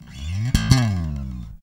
Index of /90_sSampleCDs/Roland LCDP02 Guitar and Bass/BS _Jazz Bass/BS _E.Bass FX